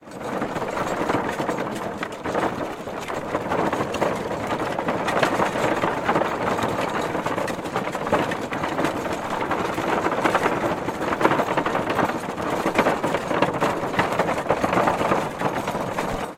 Звуки вагонетки
Скрип деревянной тележки